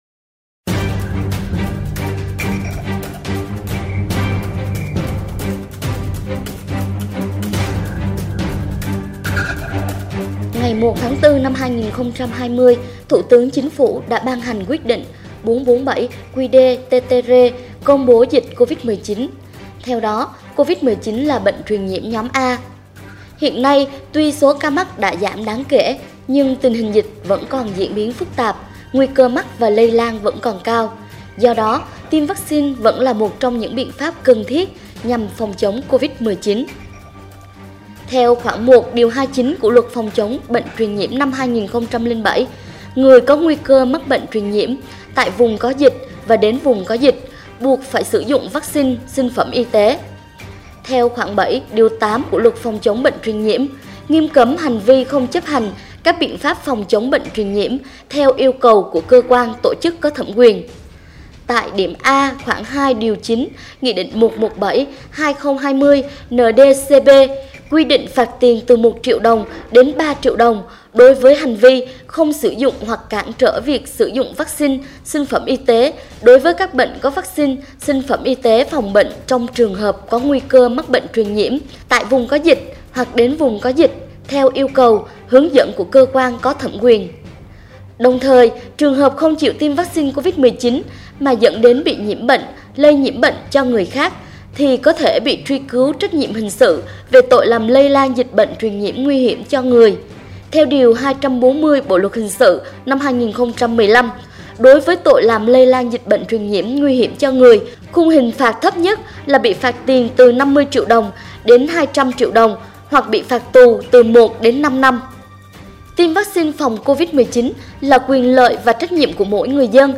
Bài phát thanh thông điệp luật lệ vắc xin